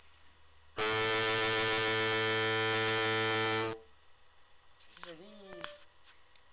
On a enregistré des notes associée aux instruments: flûte ,guitare violon et basson.
et le basson